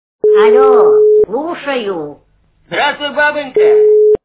» Звуки » звуки Телефонов » Алё! Слушаю! - Здравствуй, бабонька!